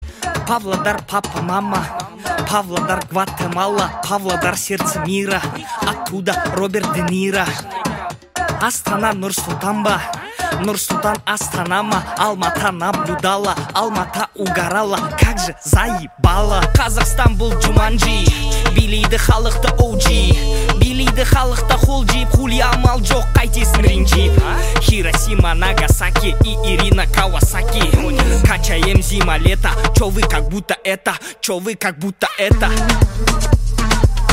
громкие
казахские